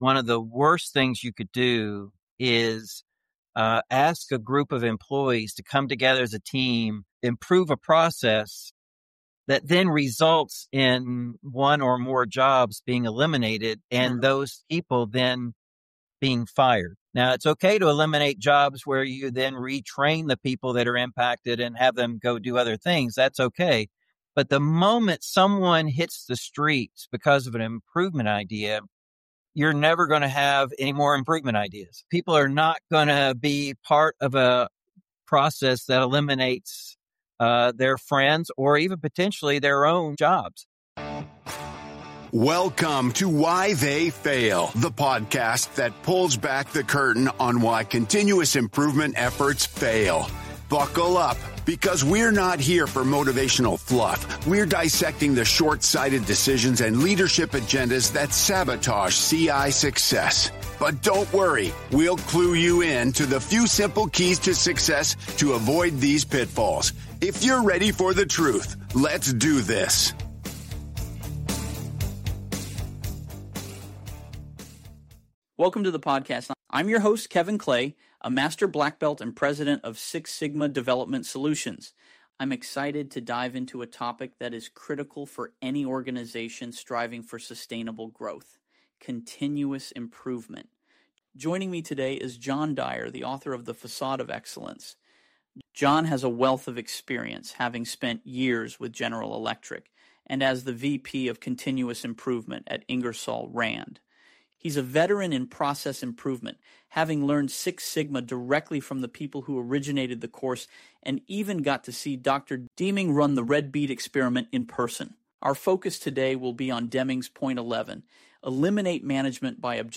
People-powered, AI-Generated